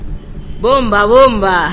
Ejemplo: avisa con voz..
Esta es la captura de la pantalla del caso que dice (con voz) bomba.